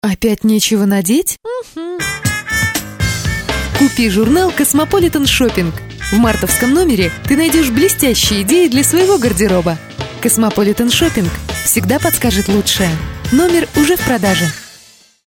Радиоанонсы прозвучат в эфире Love Radio в Москве и Санкт-Петербурге.